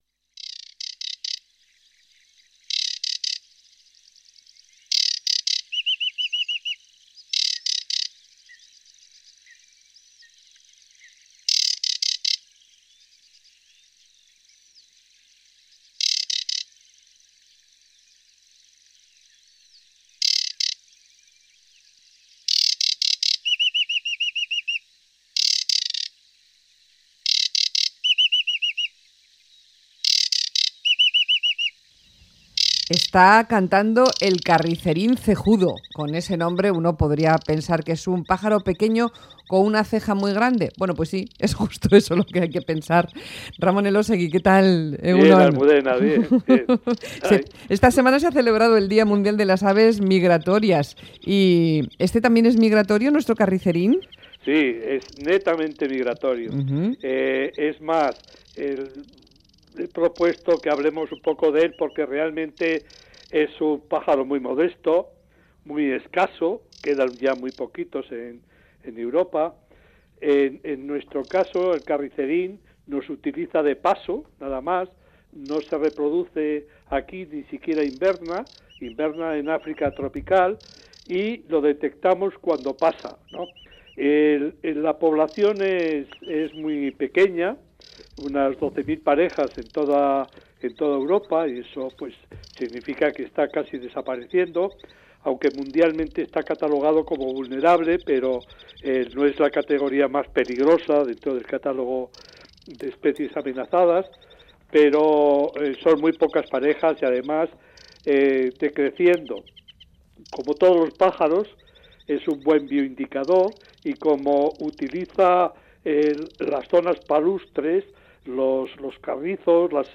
Carricerín cejudo